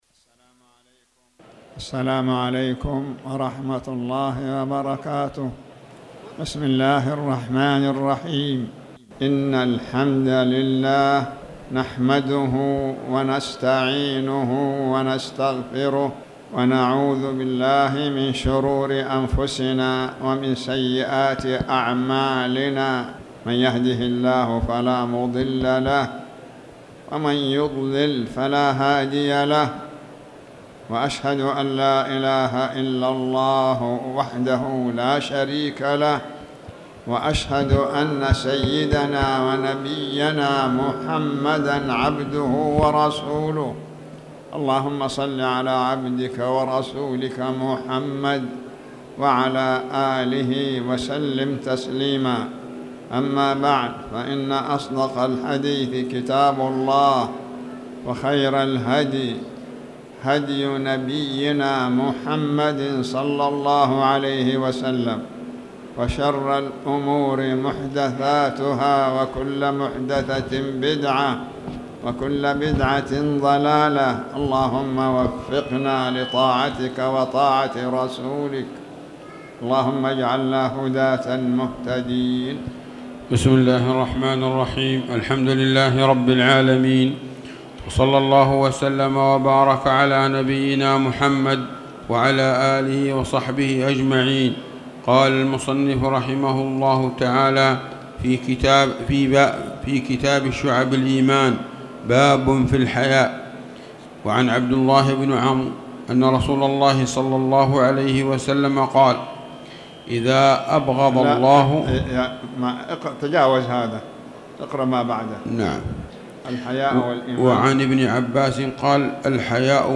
تاريخ النشر ٨ ربيع الثاني ١٤٣٩ هـ المكان: المسجد الحرام الشيخ